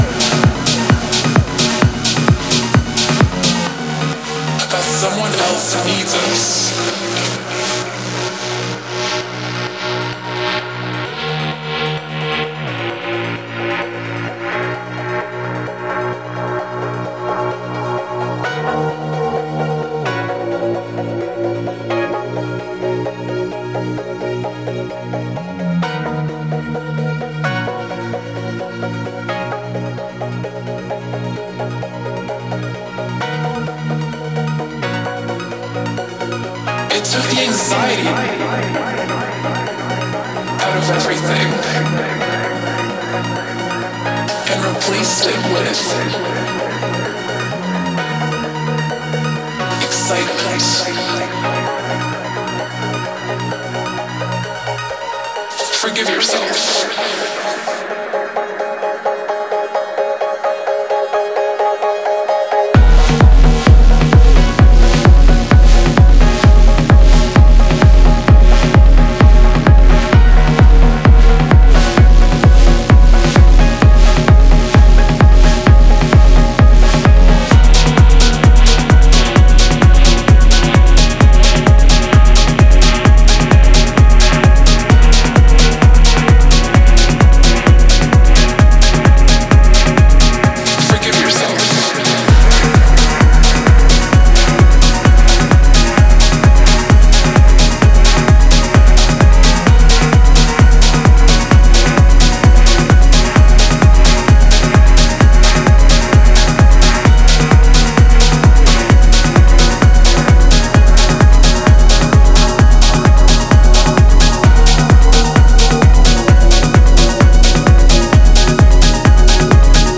Styl: Techno